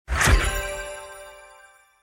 SFX_Magic_Card.mp3